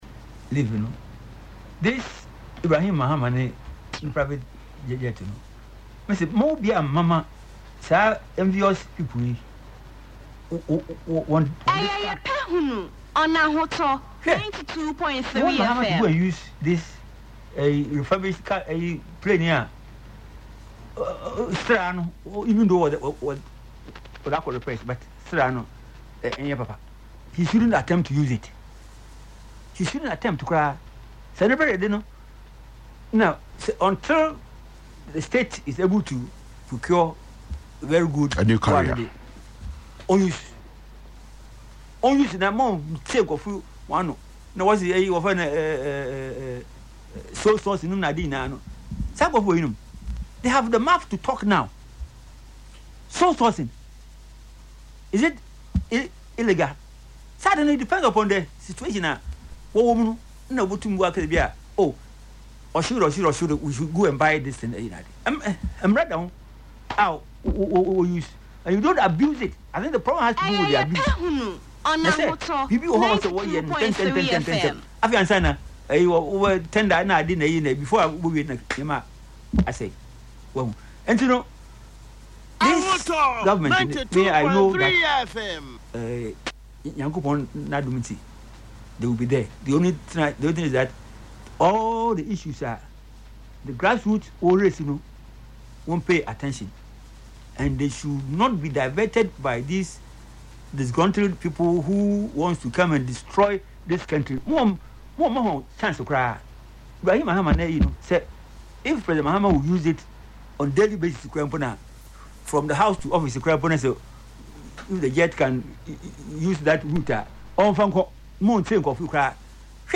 Speaking on Ahotor FM’s Yepe Ahunu show on Saturday, March 28, he stated that the current presidential jet is unfit for use and should not be considered for official travel until it is properly replaced or upgraded.